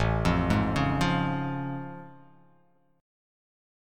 A+M7 chord